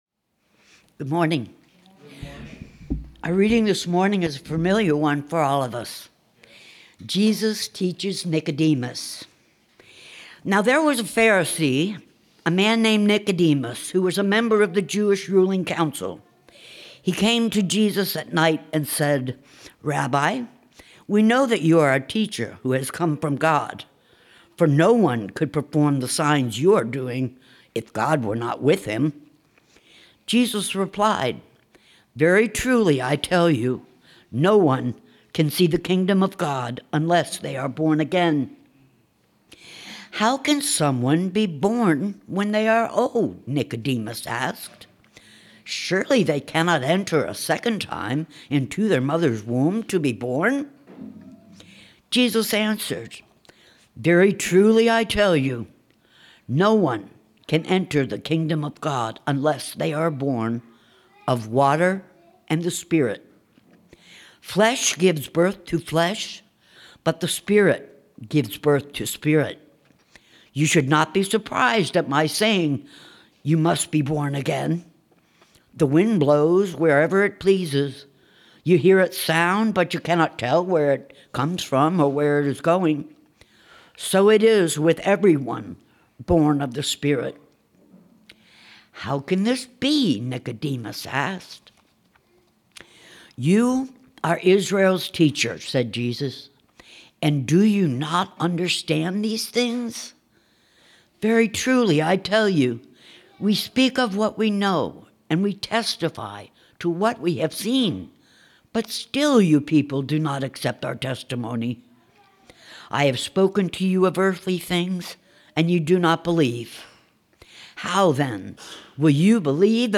August 17, 2025 Sermon Audio - Christ United Methodist Church